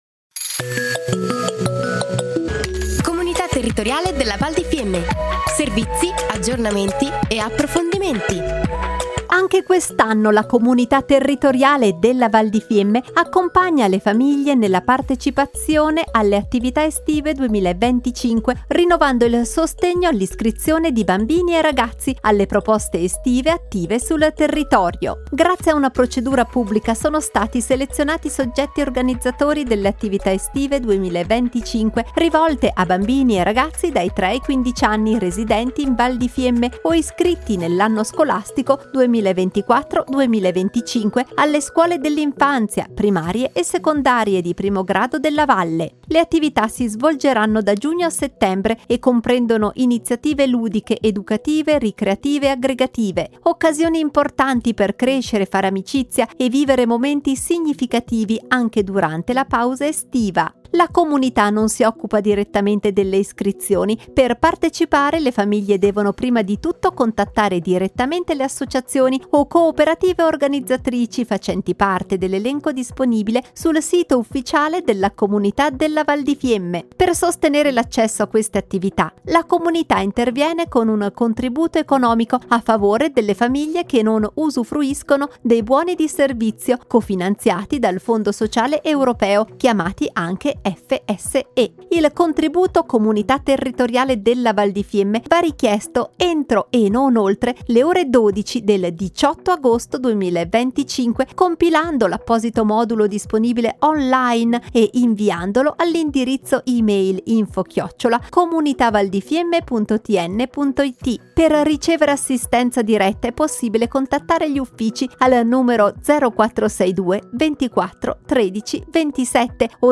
Puntata nr. 10 - Contributo per partecipazione attività estive 2025 (per bambini e ragazzi) / Anno 2025 / Interviste / La Comunità si presenta tramite Radio Fiemme / Aree Tematiche / Comunità Territoriale della Val di Fiemme - Comunità Territoriale della Val di Fiemme